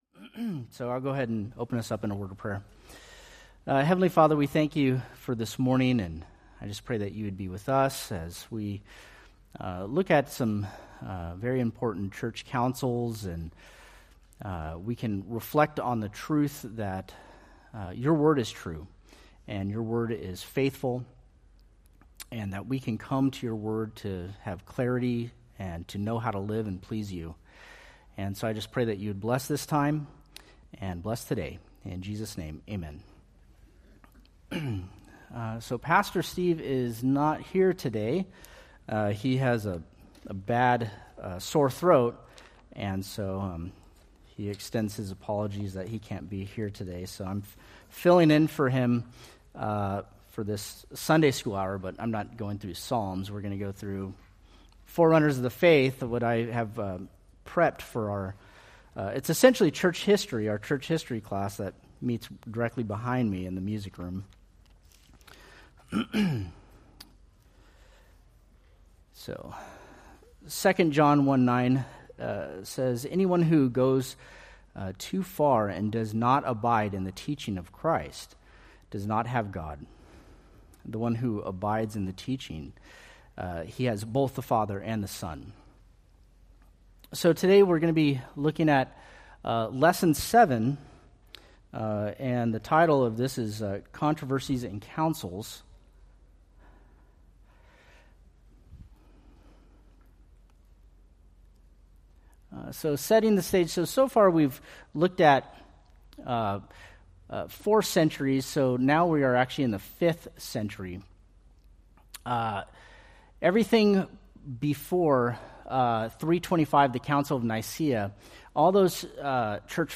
Date: Mar 9, 2025 Series: Forerunners of the Faith Grouping: Sunday School (Adult) More: Download MP3